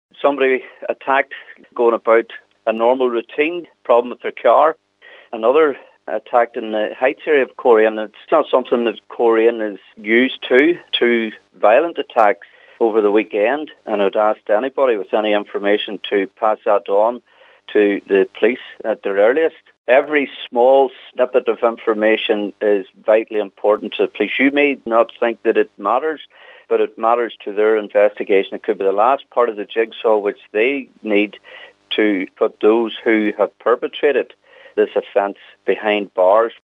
Councillor George Duddy says the violence is unacceptable: